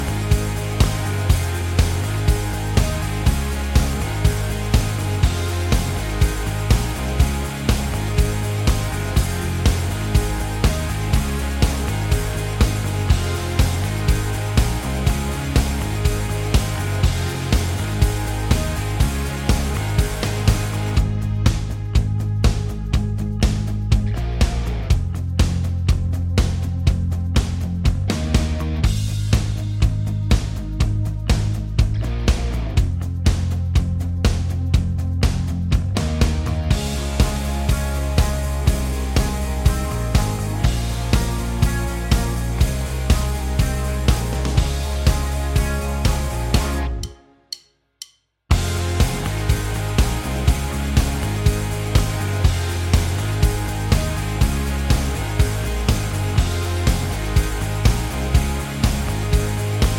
Minus Main Guitars For Guitarists 3:40 Buy £1.50